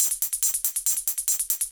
Index of /musicradar/ultimate-hihat-samples/140bpm
UHH_ElectroHatC_140-01.wav